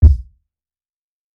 TC3Kick3.wav